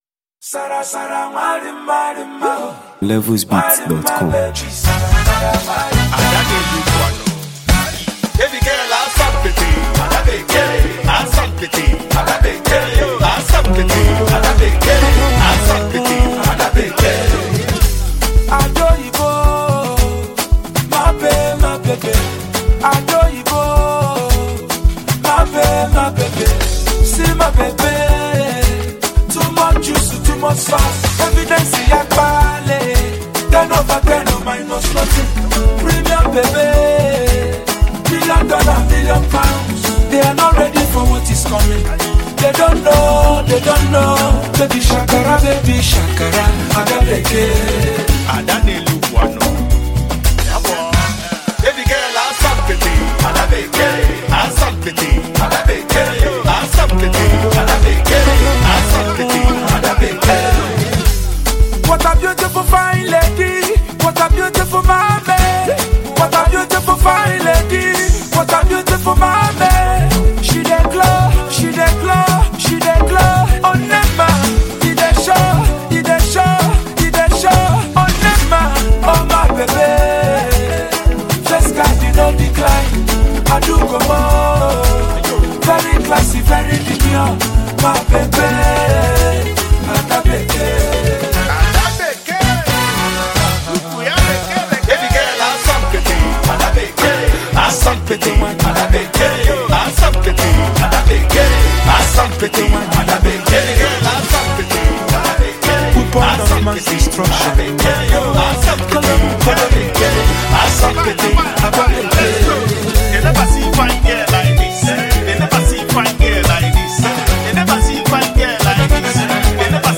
Nigerian highlife maestro and celebrated hitmaker